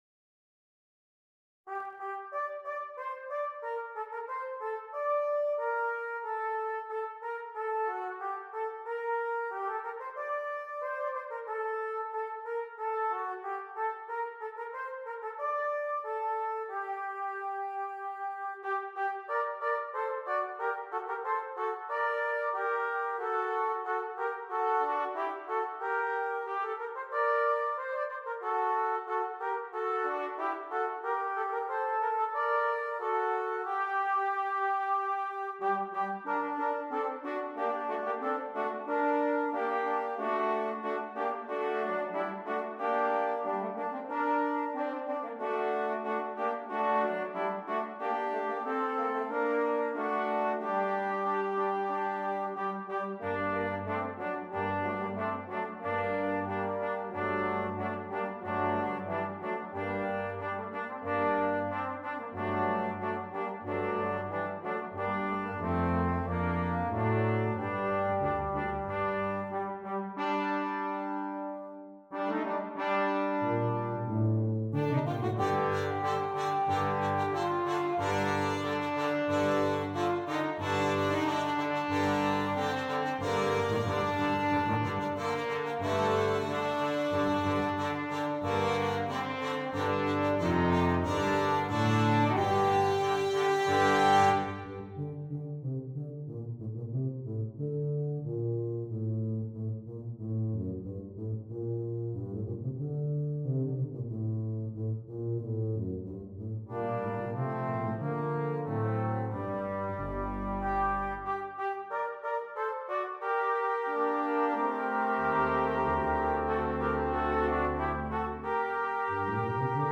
Christmas
Brass Quintet
Traditional